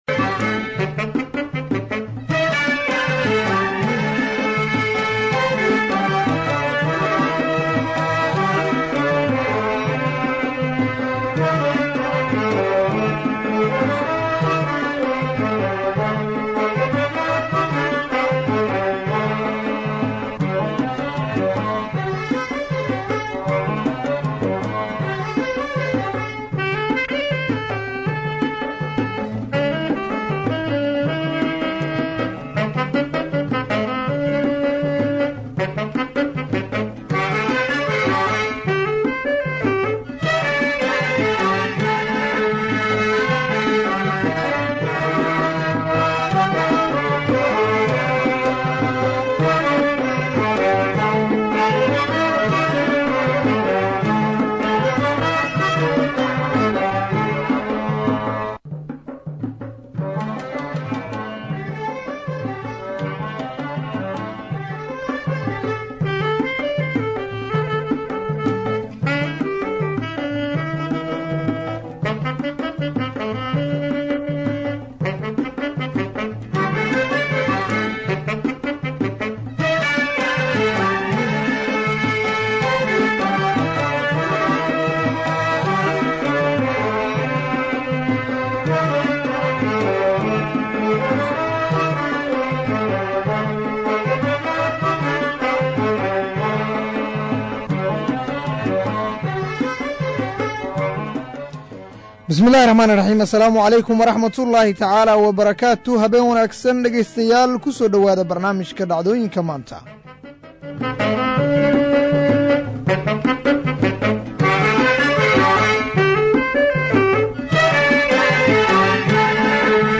Barnaamijka waxaa diirada lagu saaraa raad raaca ama falanqeynta dhacdooyinka maalintaas taagan, kuwa ugu muhiimsan, waxaana uu xambaarsan yahay macluumaad u badan Wareysiyo.